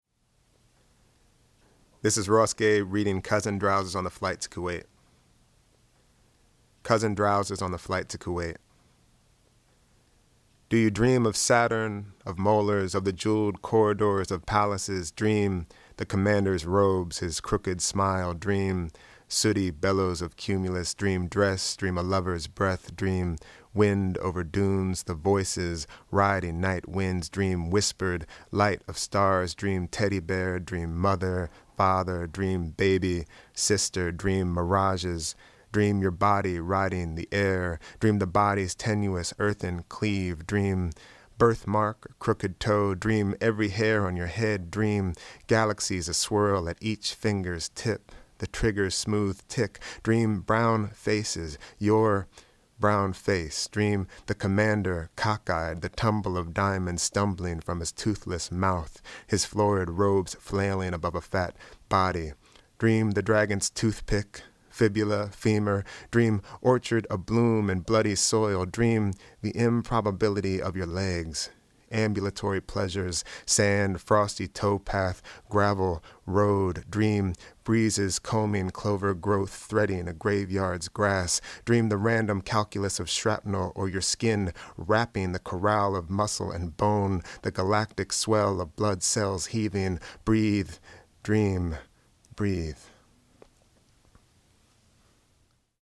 Ross Gay reads his poem “Cousin Drowses on the Flight to Kuwait” which appears in From the Fishouse: An Anthology of Poems That Sing, Rhyme, Resound, Syncopate, Alliterate, and Just Plain Sound Great, forthcoming in April from Persea Books, an independent press in New York City.